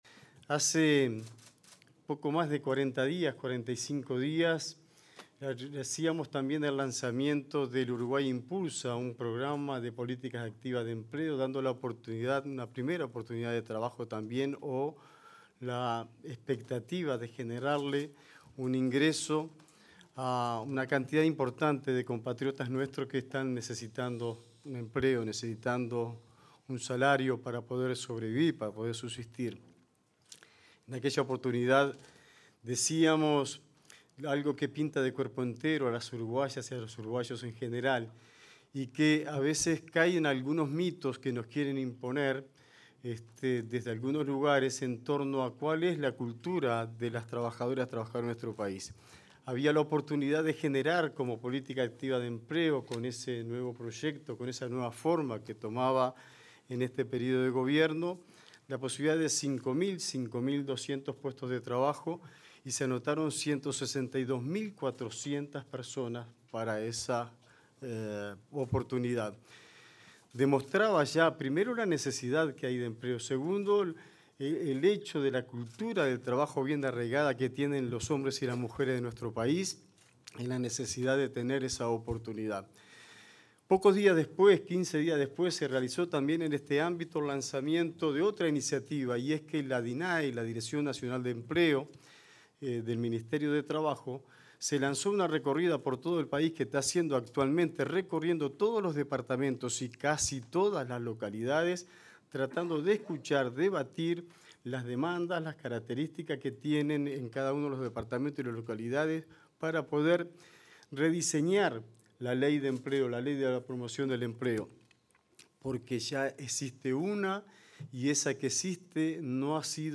Palabras de autoridades en lanzamiento del programa Yo Estudio y Trabajo
Palabras de autoridades en lanzamiento del programa Yo Estudio y Trabajo 06/10/2025 Compartir Facebook X Copiar enlace WhatsApp LinkedIn La vicepresidenta de la República, Carolina Cosse, y los ministros de Trabajo y Seguridad Social, Juan Castillo; Industria, Energía y Minería, Fernanda Cardona; Educación y Cultura, José Carlos Mahía, y Desarrollo Social, Gonzalo Civila, compartieron la mesa de oradores en la presentación de la 14.ª edición del programa Yo Estudio y Trabajo.